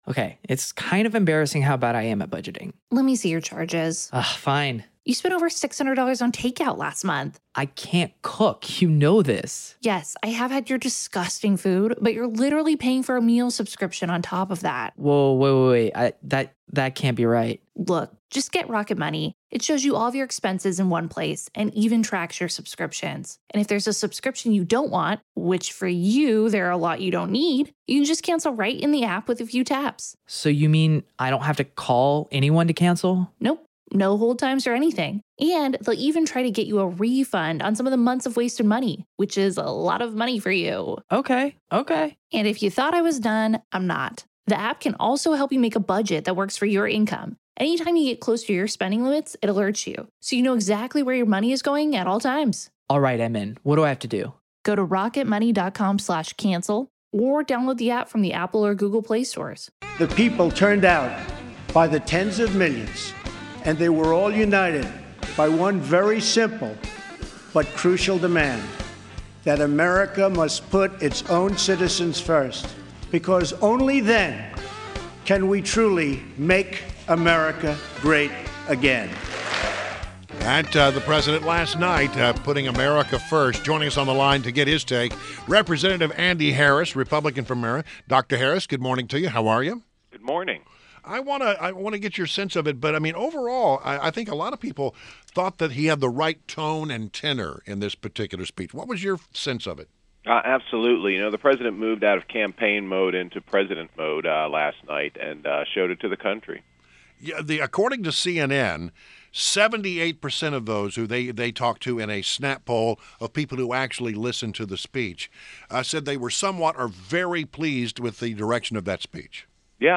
INTERVIEW — CONGRESSMAN ANDY HARRIS – R-MD (M.D.)